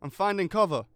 Voice Lines / Combat Dialogue